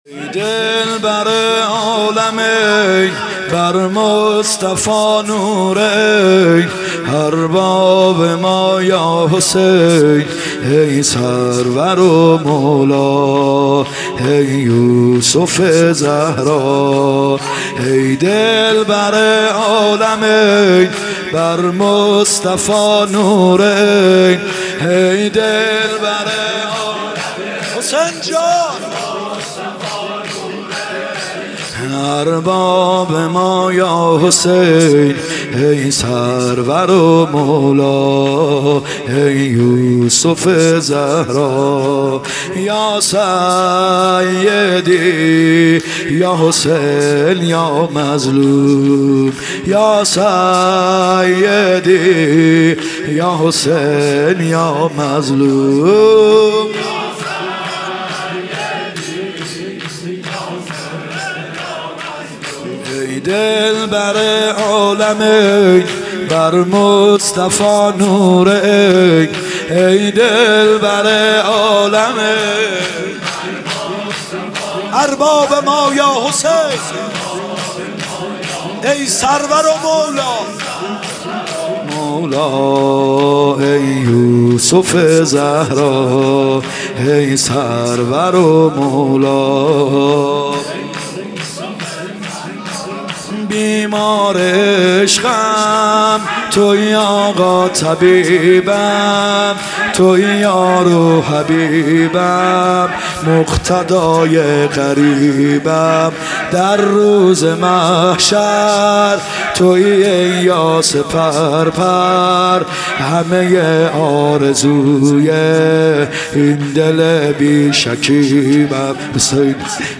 شهادت امام صادق ع 97 شور ( ای دلبر عالمین بر مصطفی نور عین
شهادت امام صادق ع 97